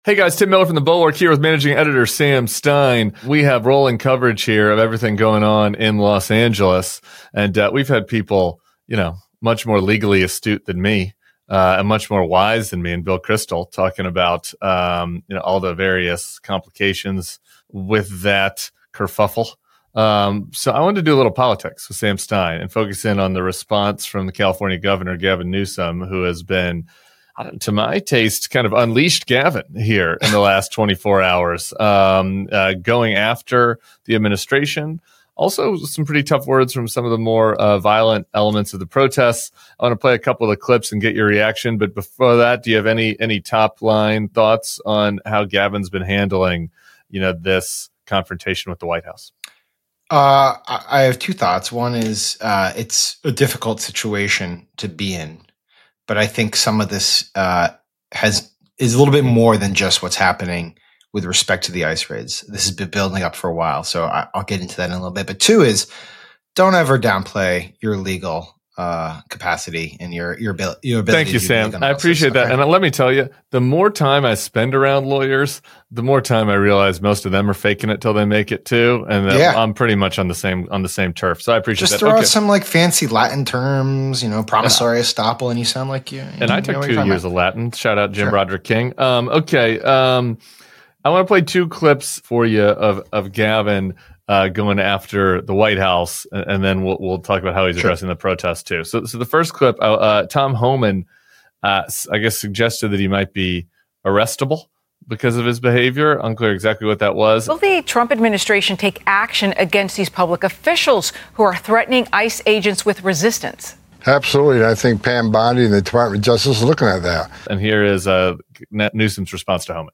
Tim Miller and Sam Stein talk California Governor Gavin Newsom’s forceful response to Donald Trump and Tom Homan’s threats and deployment of the National Guard on anti-ICE protesters in Los Angeles, and how Democratic lawmakers can learn from his leadership at this defining mo...